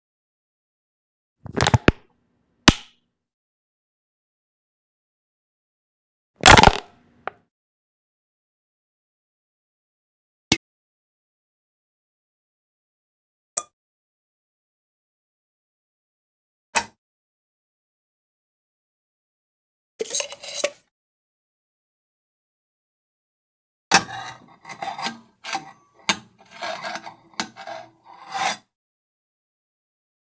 household
Can Soft Drink Noise 2